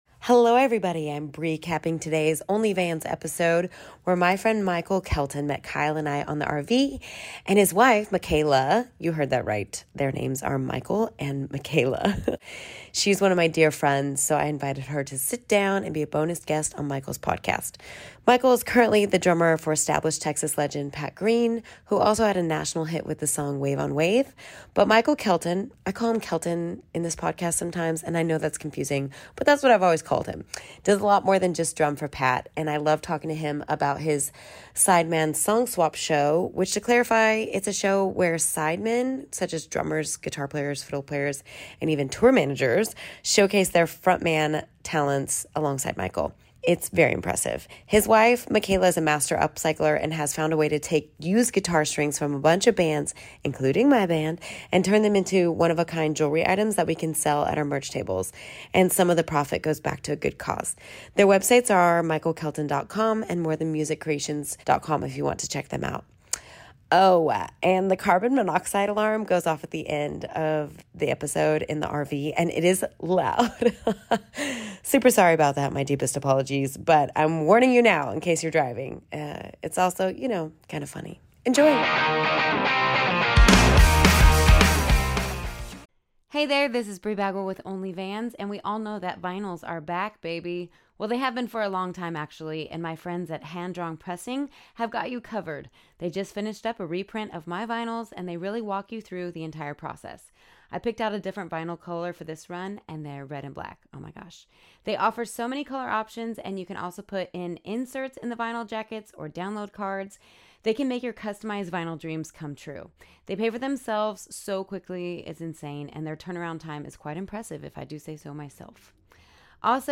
Oh! And the carbon monoxide alarm goes off at the end of the episode in the RV and it is loud.